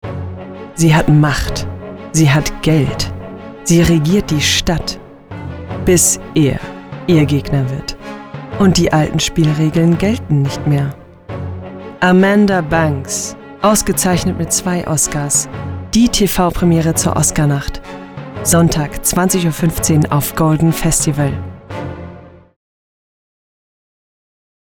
Tief, freundlich, warm
Sprechprobe: Industrie (Muttersprache):
Trailer.mp3